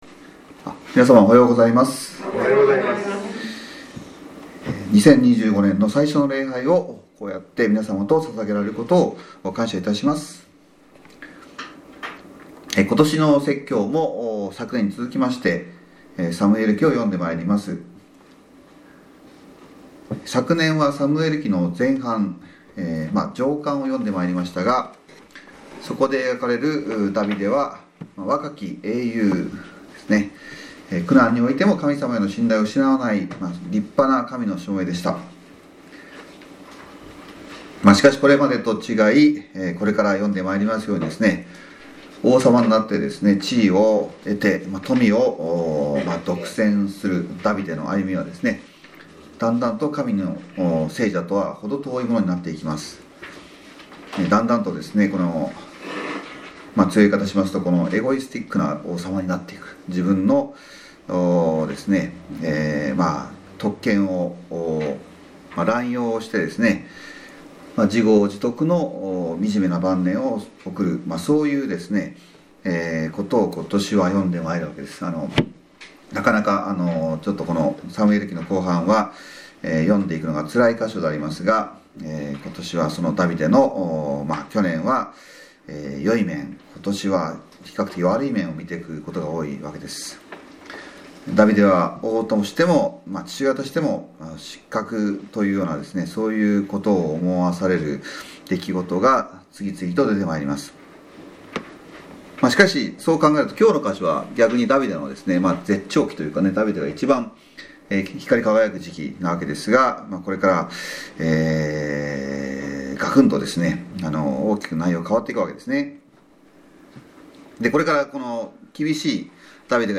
みなさま、おはようございます。主の年2025年の最初の礼拝を皆さまと共に主に献げられることを感謝します。